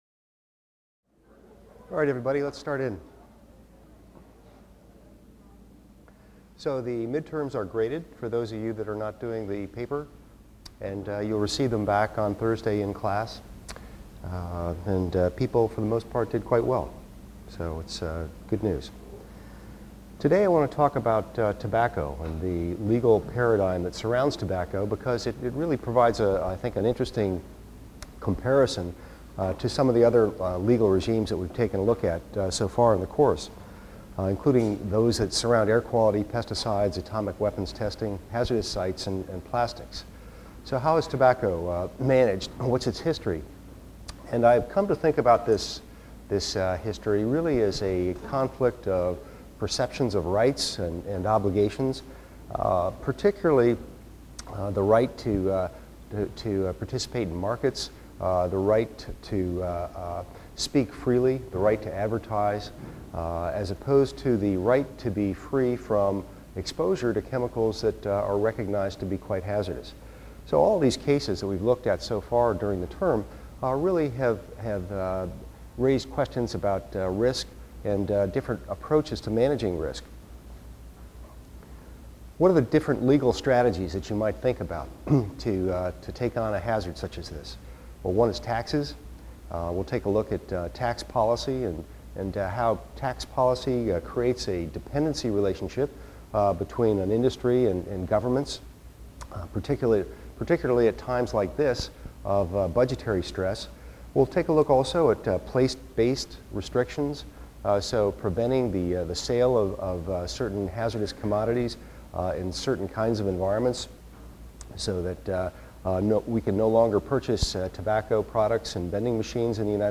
EVST 255 - Lecture 15 - The Tobacco Paradigm | Open Yale Courses